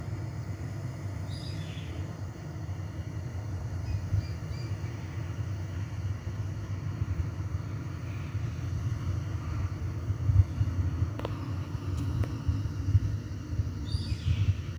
Chimachima (Daptrius chimachima)
Nombre en inglés: Yellow-headed Caracara
Localización detallada: Lago Salto Grande
Condición: Silvestre
Certeza: Observada, Vocalización Grabada
Chimachima.mp3